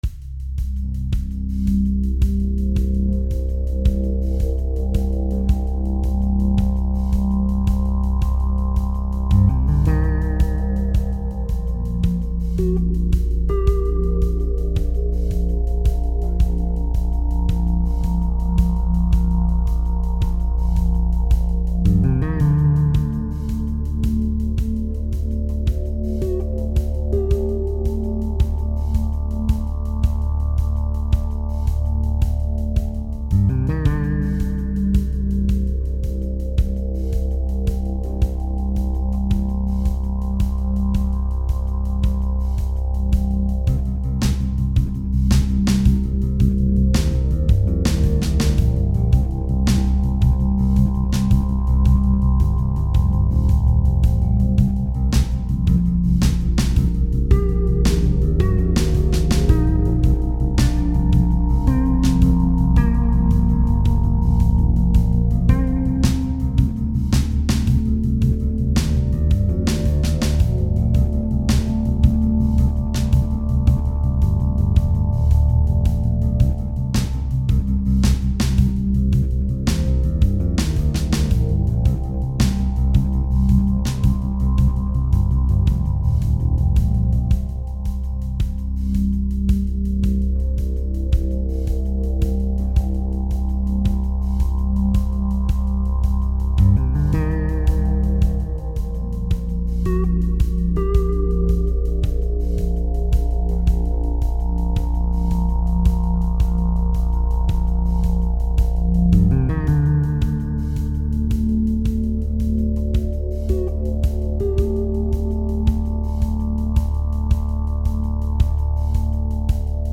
Dark ambience with an odd time signature